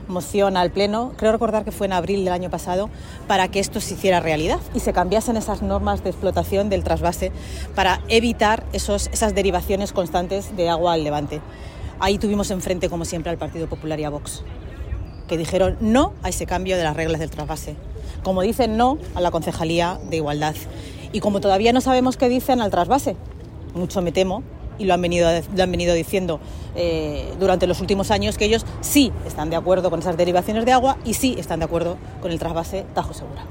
A preguntas de los medios en el parque de la Vega tras la concentración en contra de la violencia machista, Noelia de la Cruz ha recordado que el grupo socialista presentó el año pasado una moción en el pleno del Ayuntamiento para cambiar esas normas de explotación y que tuvo en frente a Partido Popular y Vox que votaron en contra, por ejemplo, de incorporar caudales ecológicos para garantizar agua en cantidad y calidad suficiente para Toledo y el río Tajo.